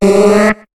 Cri de Queulorior dans Pokémon HOME.